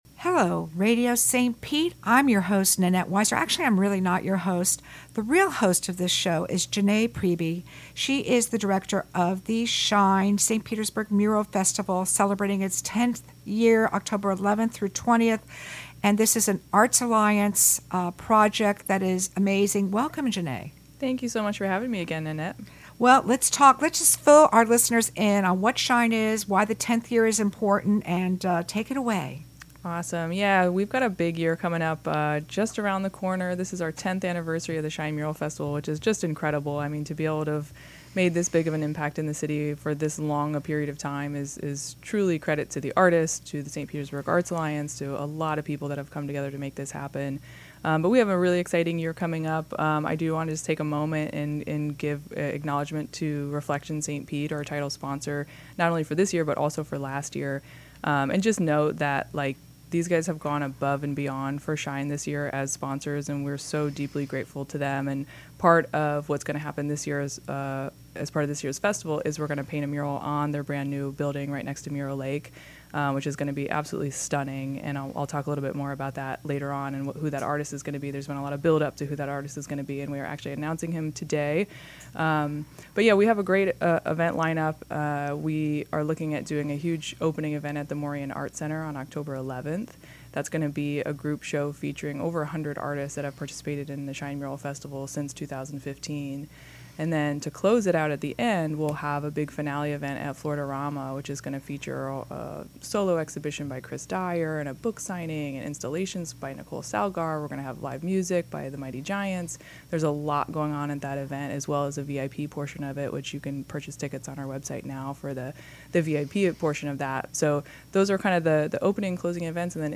Joining us in studio